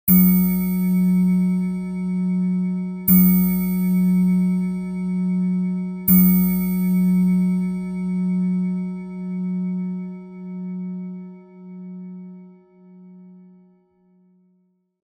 جلوه های صوتی
دانلود صدای ساعت 13 از ساعد نیوز با لینک مستقیم و کیفیت بالا